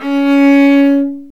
Index of /90_sSampleCDs/Roland - String Master Series/STR_Viola Solo/STR_Vla3 _ marc
STR VIOLA 06.wav